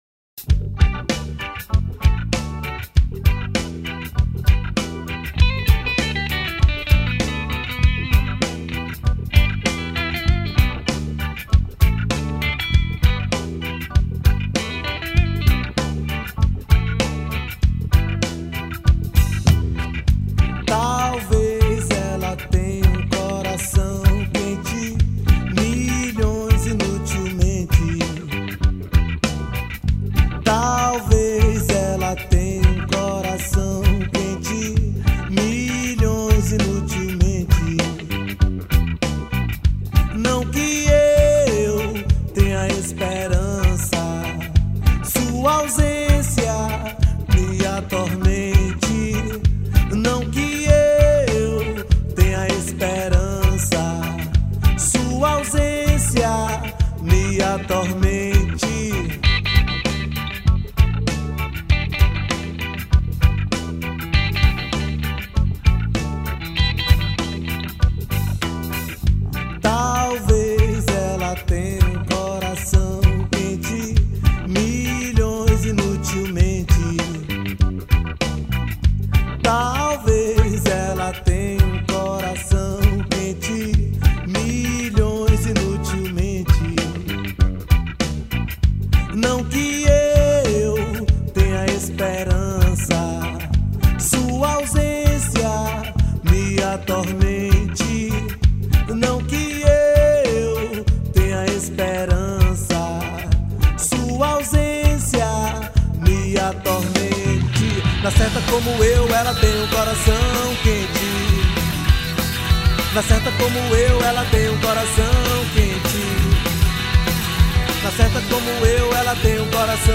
2609   03:46:00   Faixa: 1    Reggae